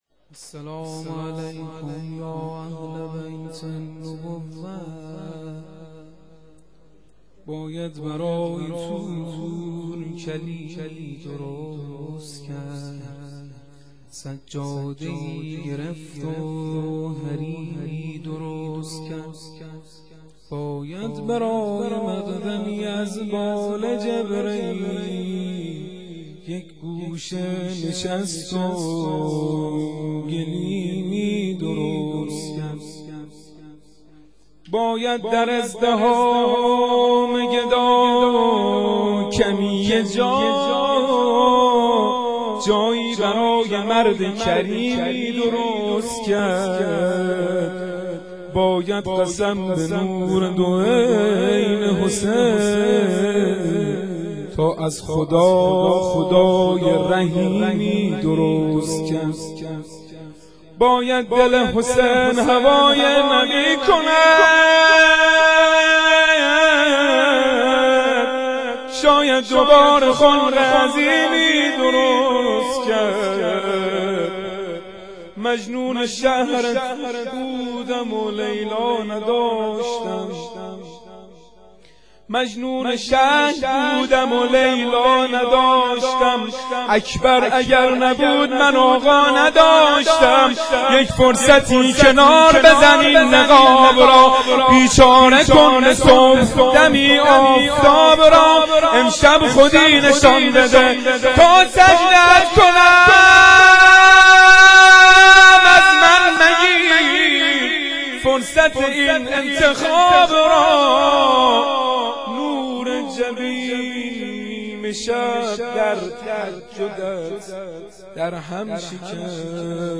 مدیحه سرایی
شام میلاد حضرت علی اکبر 1392